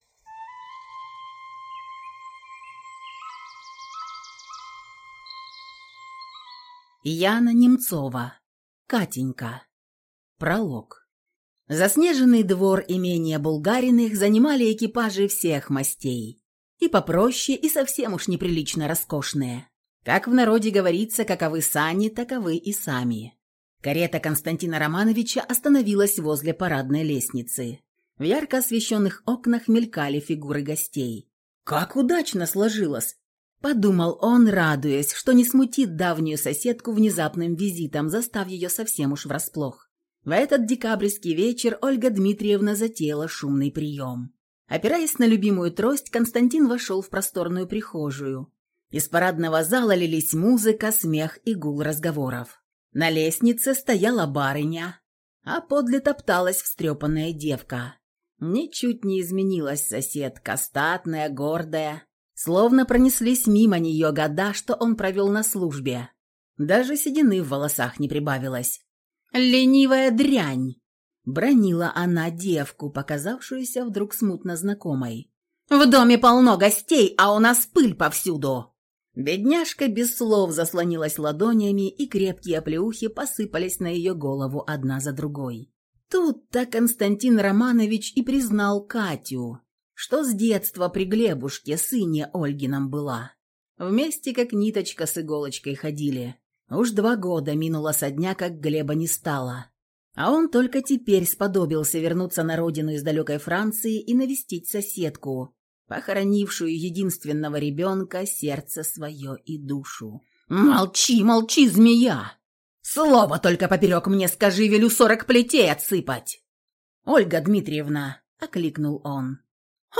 Аудиокнига Катенька | Библиотека аудиокниг